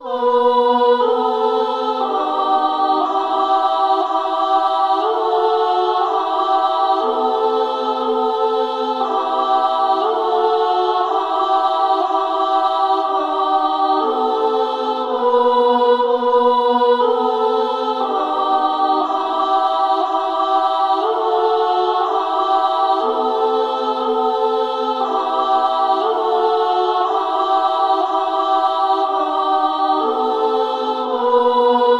合唱团妇女001 120BPM
描述：合唱团的循环。
Tag: 120 bpm Orchestral Loops Choir Loops 5.38 MB wav Key : Unknown